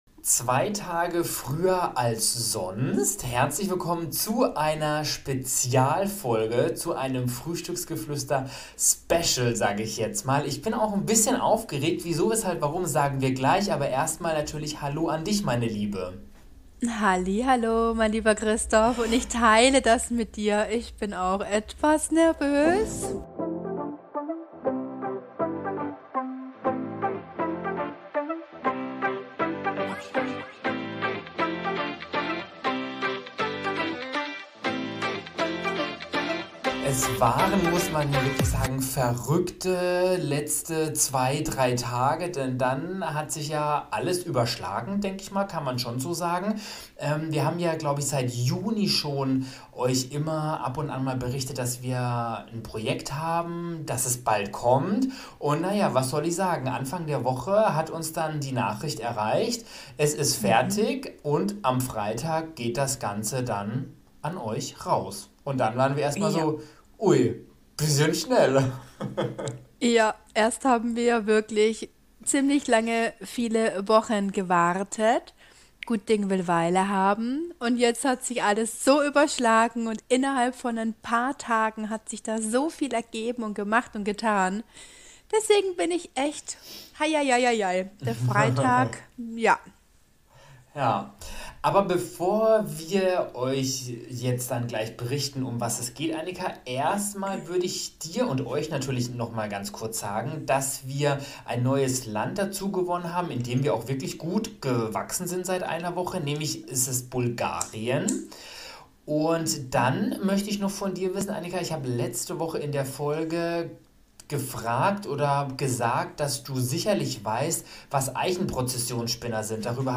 Heute können wir mit Stolz das Geheimnis lüften: wir hatten ein Zeitungsinterview mit den Fränkischen Nachrichten! Der daraus entstandene Artikel könnt Ihr heute in der gedruckten FN-Ausgabe lesen, online oder aber auch von uns heute hier vorgetragen in unserem Podcast.